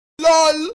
Lol Meme Meme Effect sound effects free download
Lol Meme - Meme Effect Mp3 Sound Effect